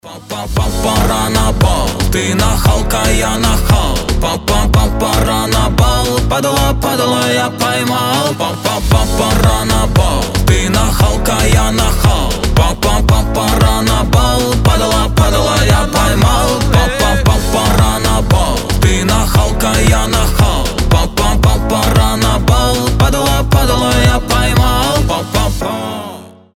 ритмичные
Хип-хоп
веселые
заводные